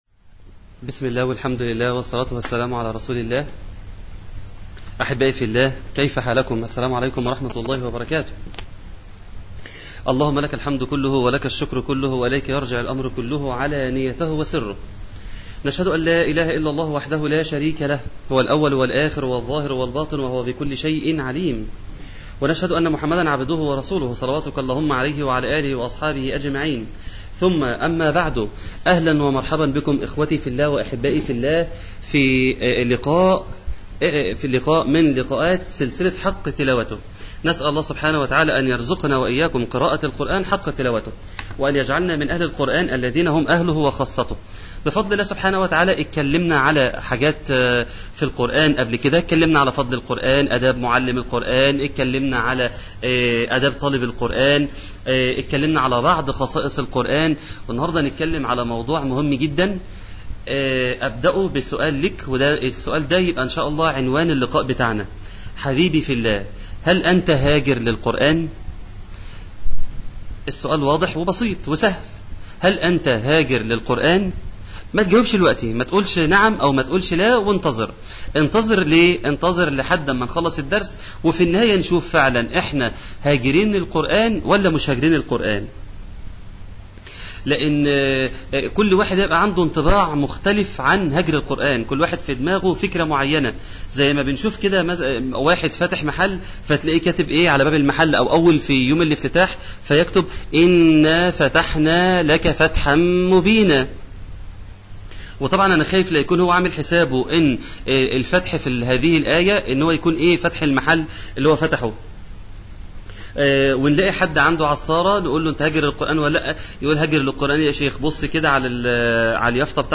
عنوان المادة الدرس السادس : هل أنت هاجر للقرآن؟